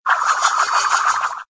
CHQ_VP_frisbee_gears.ogg